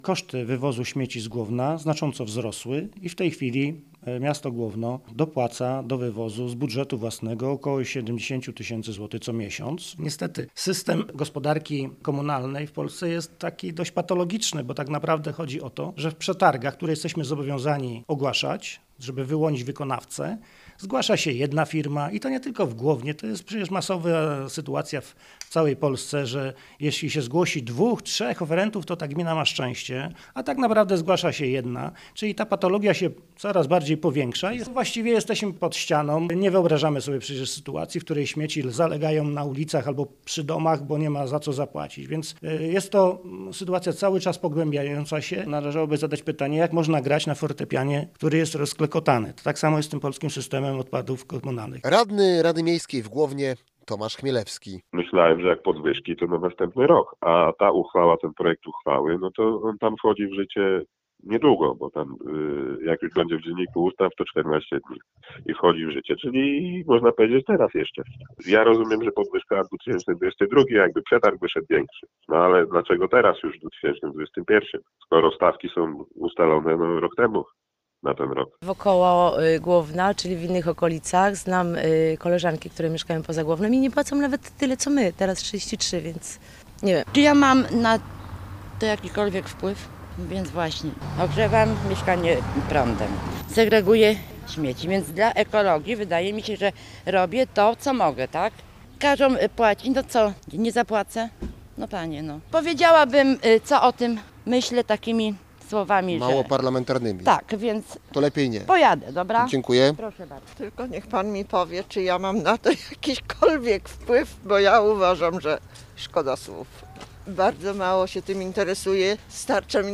Burmistrz Głowna Grzegorz Janeczek mówi, że nie ma wyjścia i stawki muszą być wyższe.